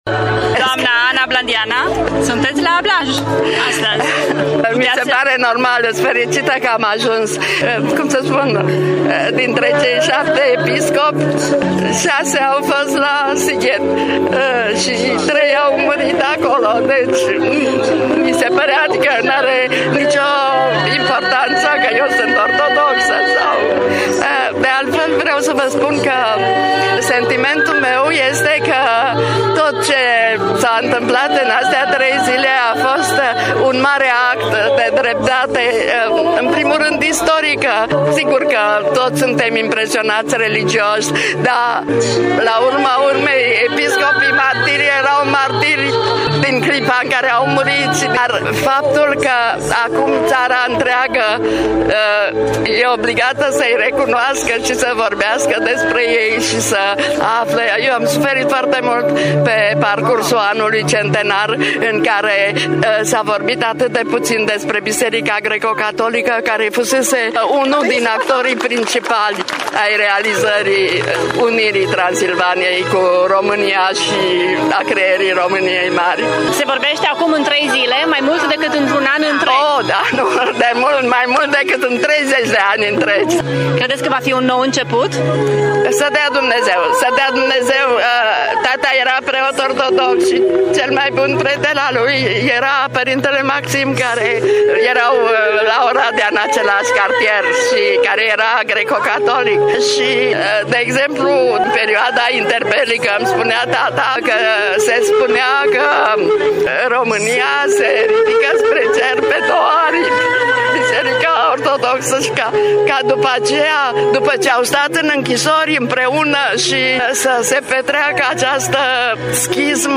Cunoscuta scriitoare și luptătoare pentru libertate civică din România, Ana Blandiana, nu putea lipsi duminică de la Blaj, a mărturisit într-un interviu în exclusivitate pentru Radio Târgu-Mureș.